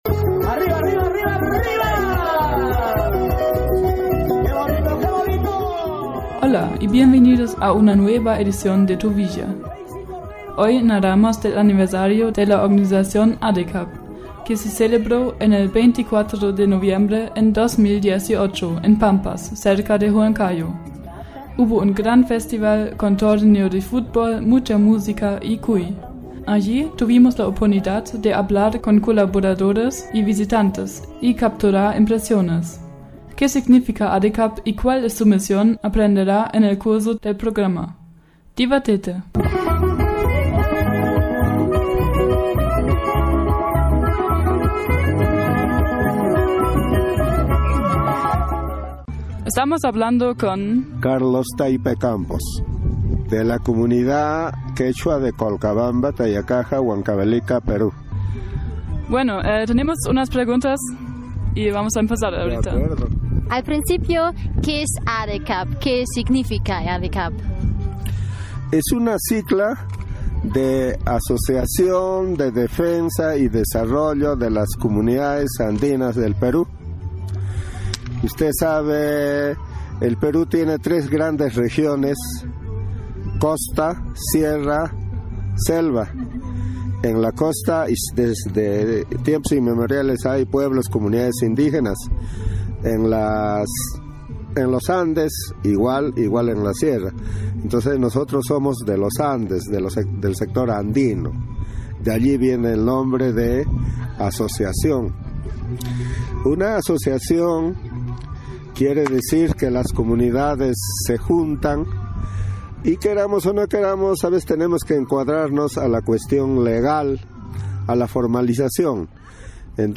ADECAP setzt sich für die Verbesserung der Lebenssituation der quechuasprachigen Gemeinden in Peru ein. Am 24. November 2018 feierte die Organisation Jahrestag und wir waren live dabei. Ein Fußballturnier auf über 3000 Metern und ein Interview in einem Bergdorf namens Colcabamba - hört selbst!
Anlässlich des Jahrestags wurde in Pampas, einem Dorf nahe der Großstadt Huancayo in den Anden Perus, ein großes Fußballturnier ausgetragen. Wir waren dabei, haben Stimmung und Atmosphäre eingefangen und durften selber erfahren, wie es ist, auf über 3000 Höhenmetern Fußball zu spielen.